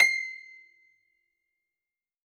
53q-pno22-C5.wav